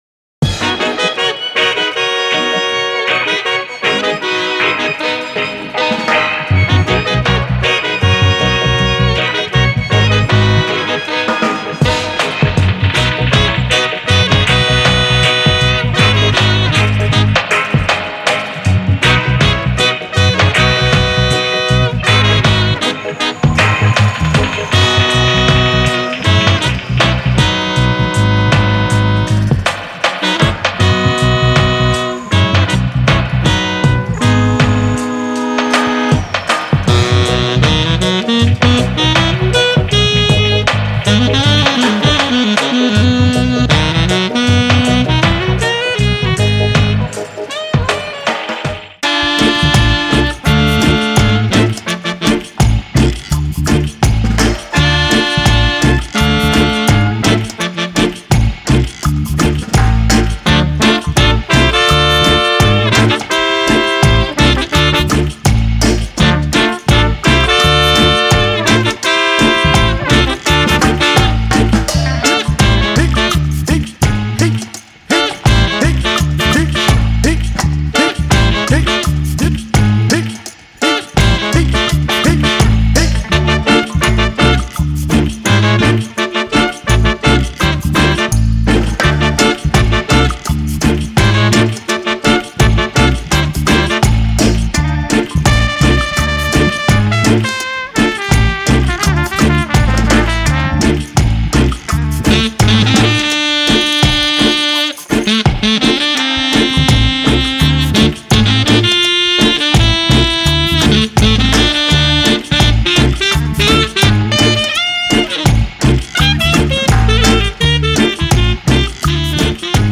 Dub Version（エコーとリバーブを組み合わせた処理）
MultiFX（イメージング、フランジャー、フェイザー、リバーブ、コンプレッション）
Spring Reverb（70年代ジャマイカ特有のスプリングリバーブ）
Genre:Dub
Tempo: 79-150 BPM